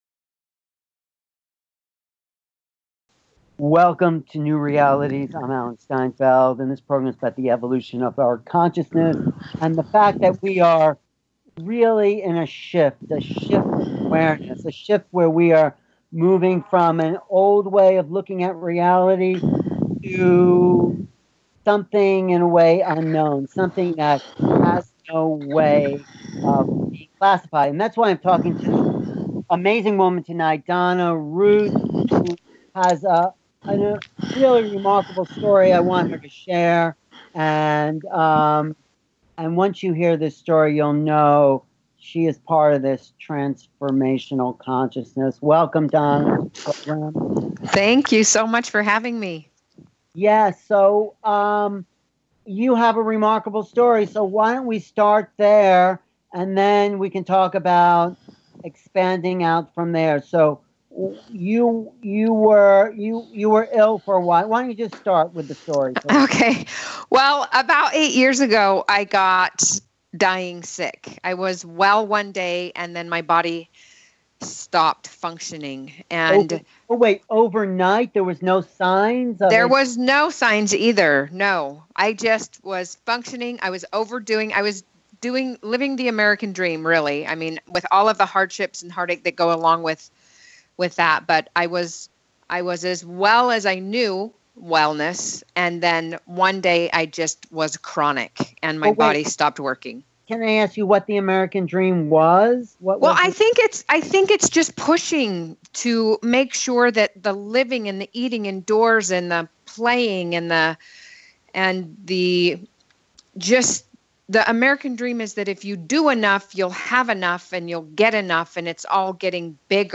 New Realities Talk Show